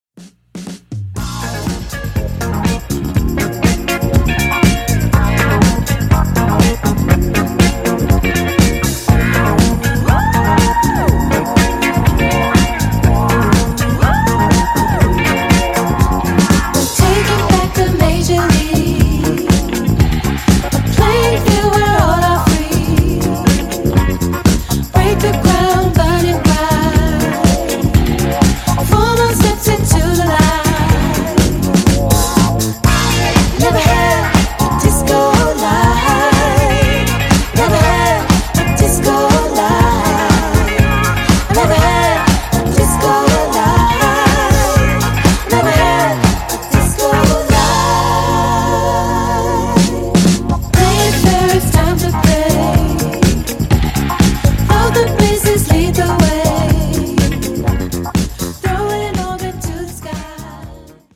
Discodelic girl band